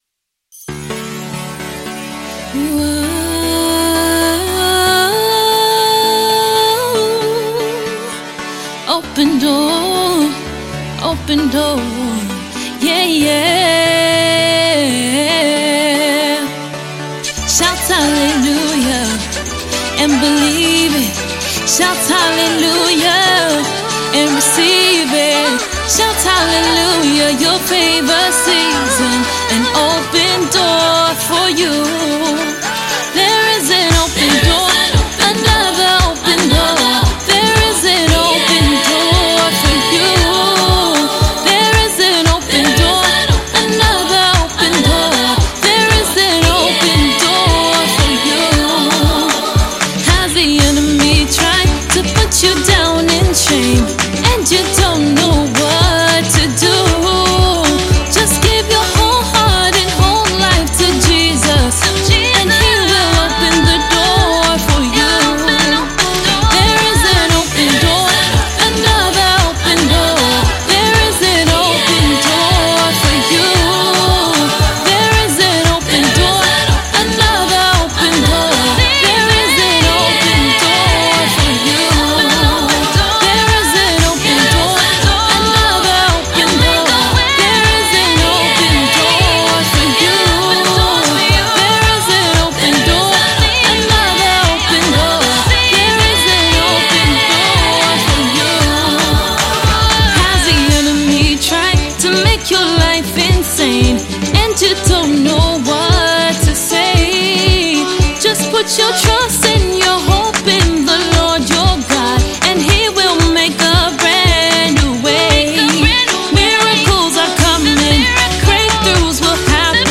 Prolific Gospel music minister